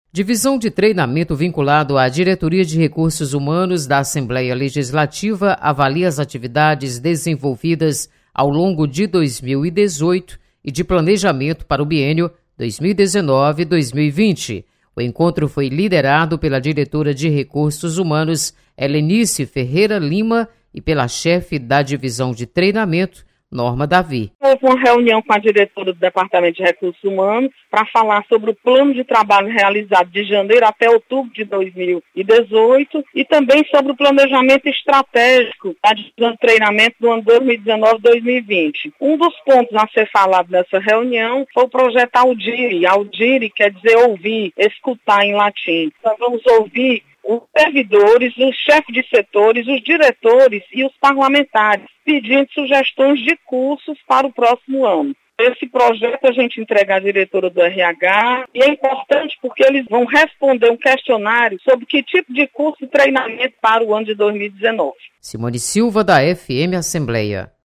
Divisão de treinamento avalia atividades de 2018. Repórter